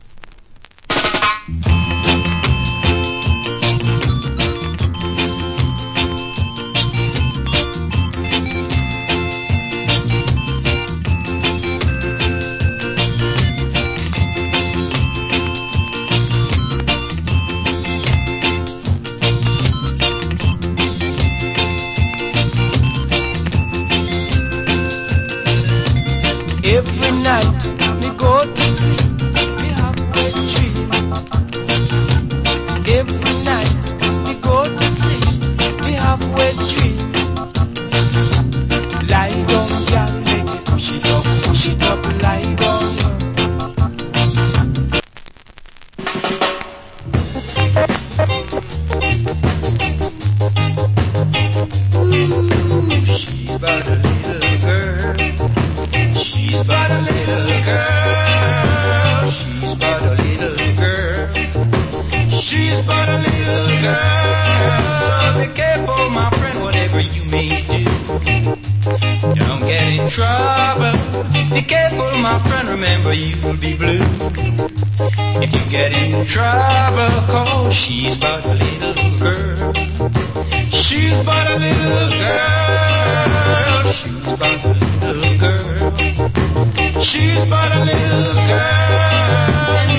Big Shot Early Reggae.